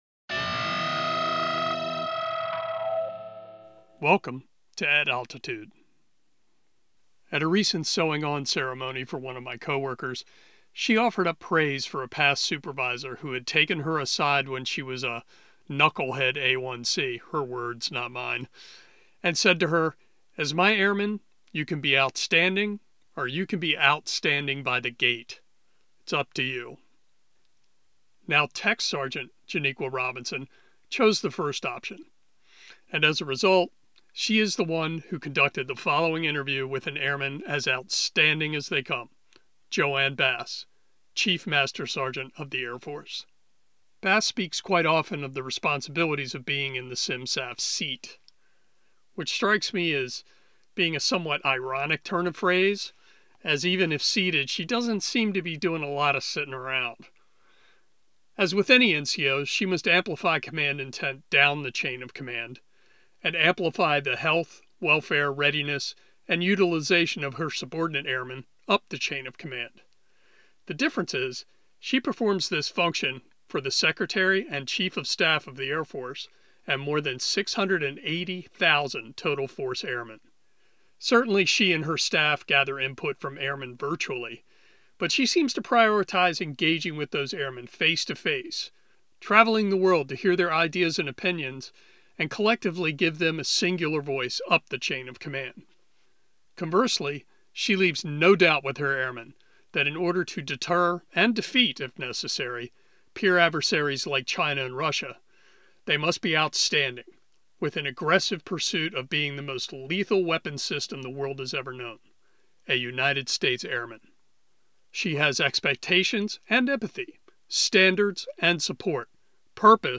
Airman magazine interview with Chief Master Sergeant of the Air Force JoAnne Bass covering her accomplishments, priorities and the need to increase the capabilities and resilience of Airmen for a potential future fight with peer adversaries.